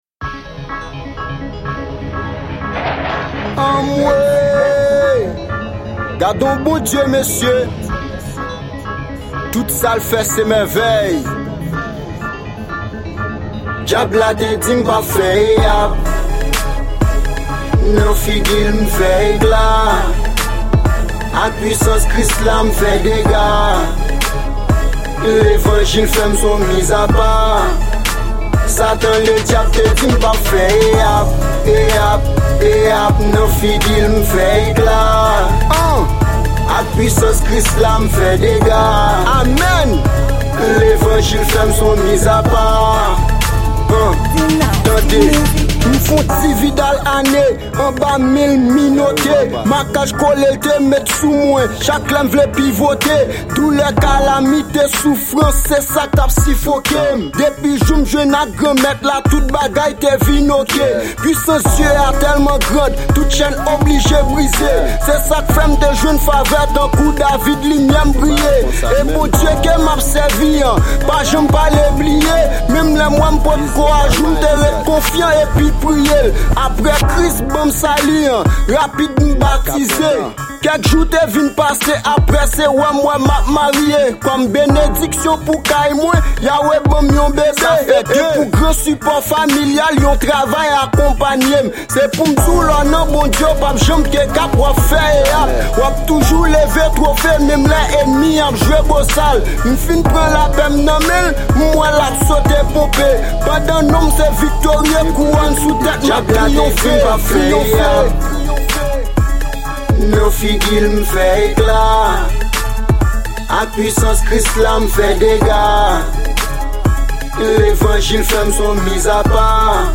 Genre: Rap Gospel.